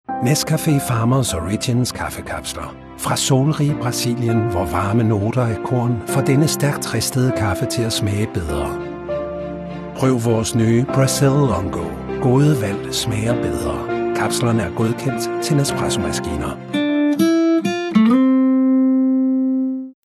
Deep, warm voice.
Television Spots